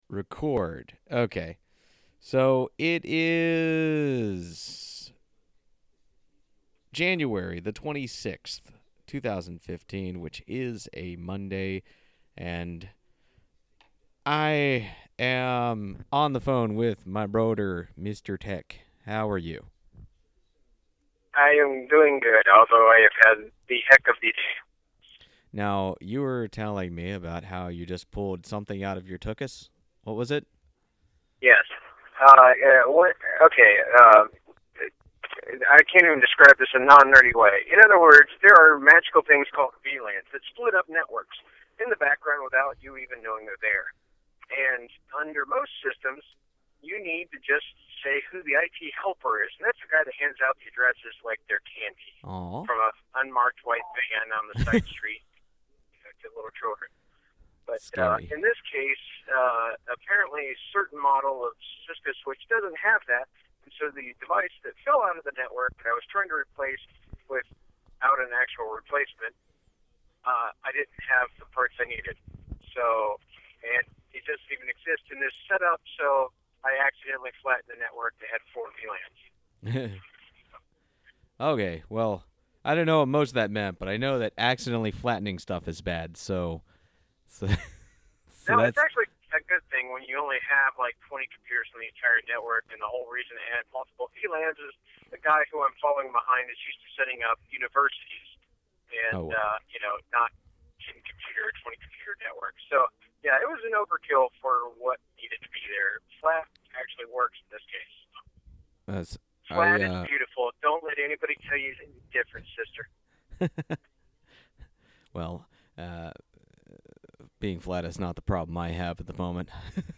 First shot- hope it’s not one of those shots heard around the world, ’cause it’s not quite pro yet.